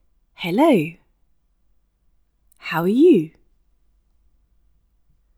To sound extra polite, engaged, and appropriate in English, experiment with British patterns of tone like the high fall when you want to sound polite, interested, or engaged in English.